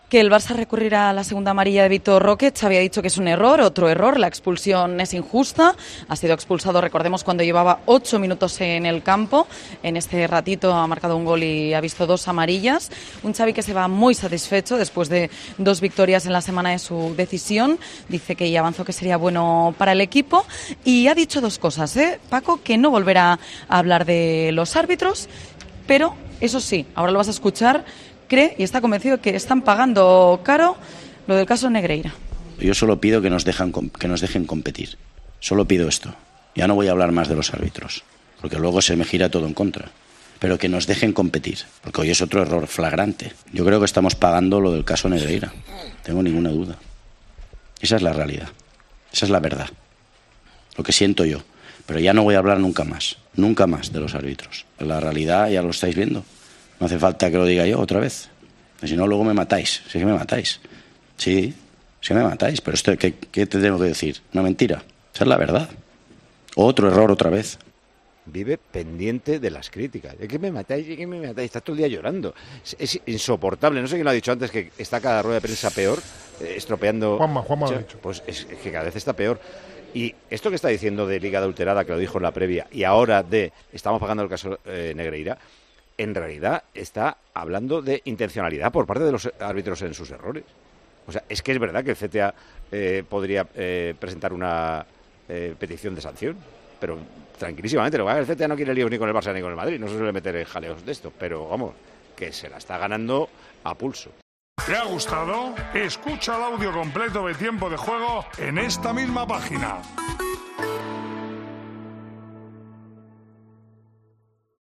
El director de Tiempo de Juego se mostró tajante después de que el entrenador del Barcelona afirmara que "están pagando el Caso Negreira".
Con Paco González, Manolo Lama y Juanma Castaño